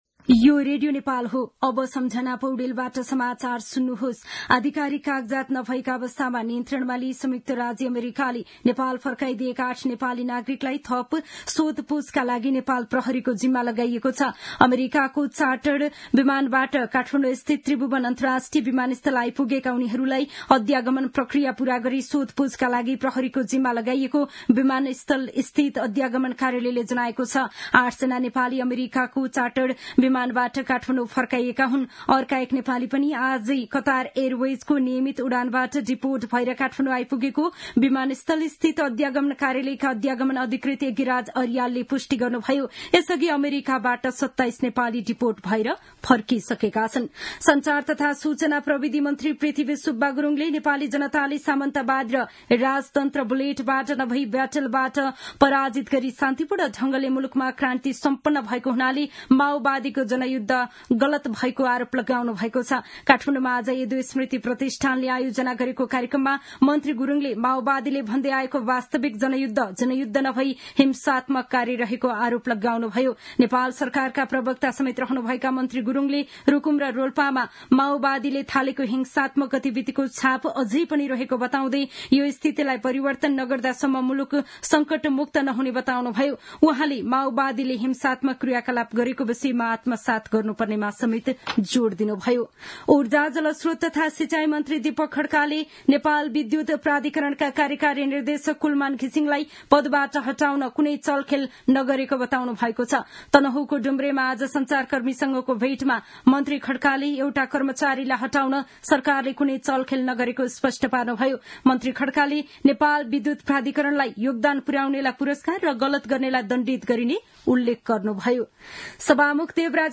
दिउँसो ४ बजेको नेपाली समाचार : २२ फागुन , २०८१
4-pm-news-1-2.mp3